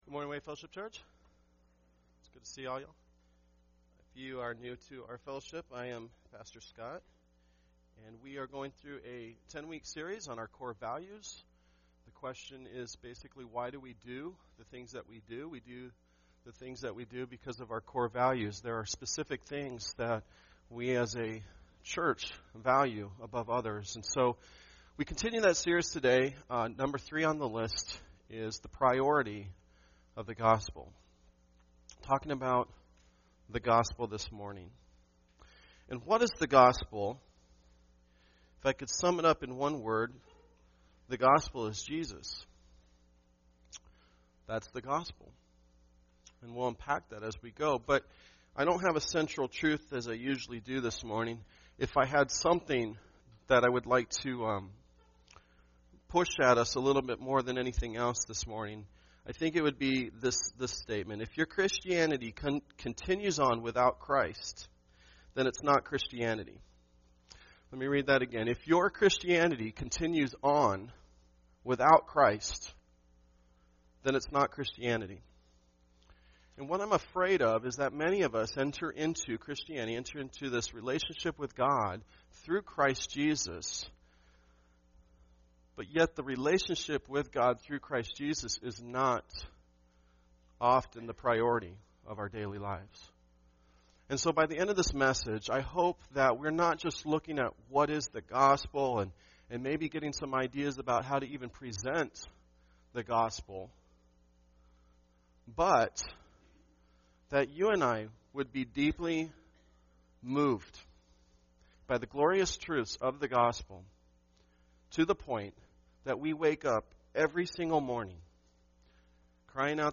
Sunday Worship
Tagged with Core Values , Sunday Sermons